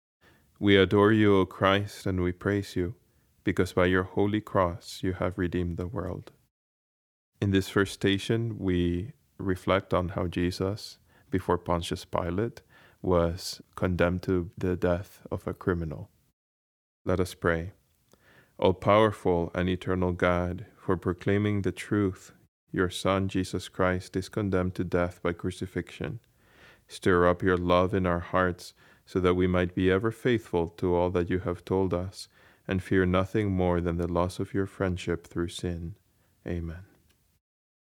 Station 1: Prayer
Baltimore-Museum-of-Art-Station-1-prayer.mp3